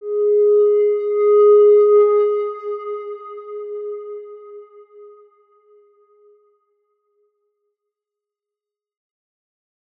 X_Windwistle-G#3-ff.wav